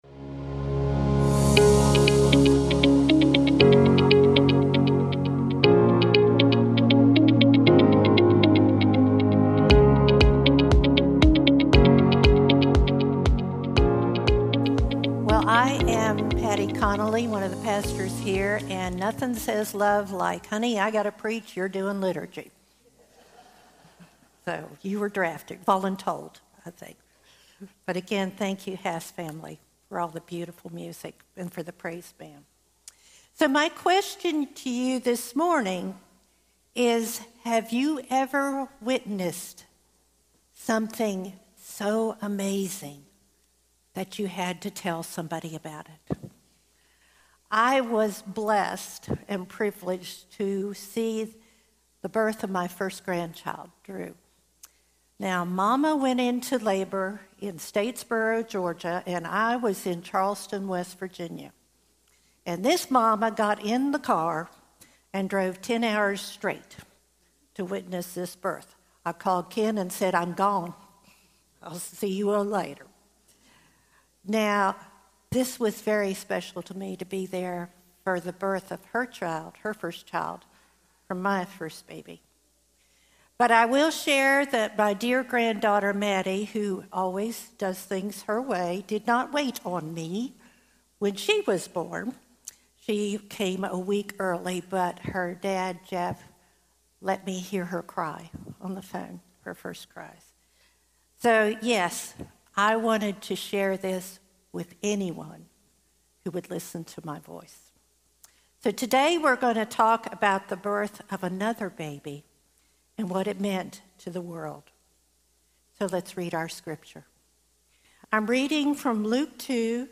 (December 29, 2024-Combined Worship Gathering) “Can I Get A Witness?”